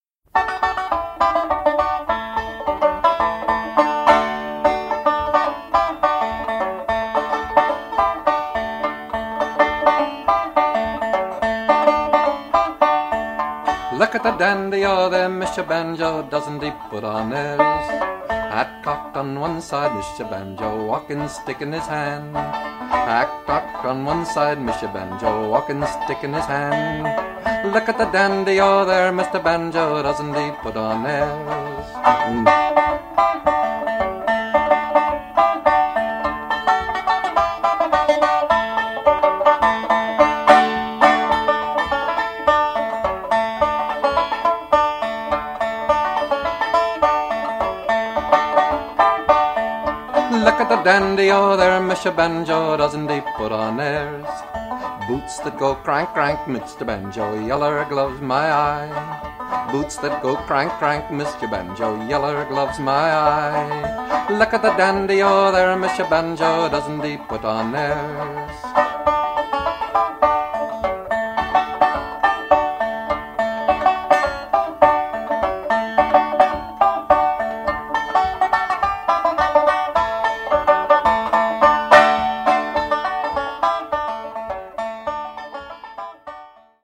Traditional
Listen to Pete Seeger perform "Mister Banjo" (mp3)